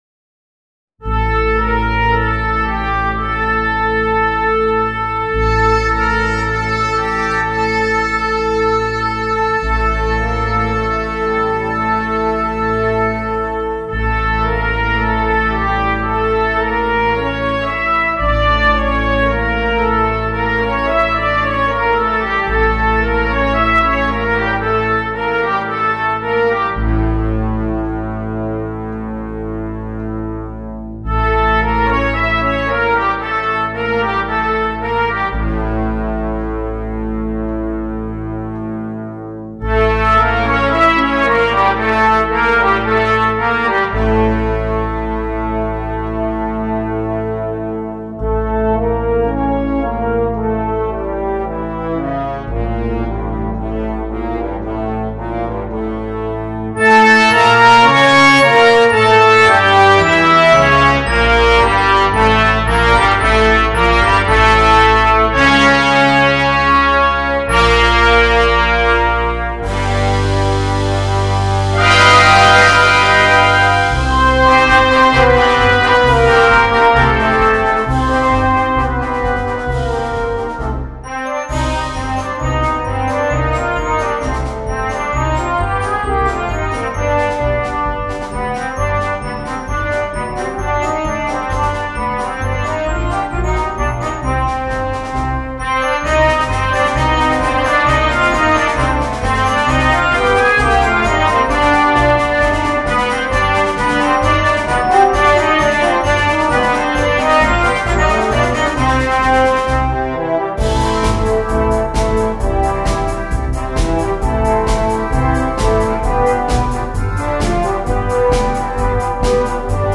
Full Band
without solo instrument
Entertainment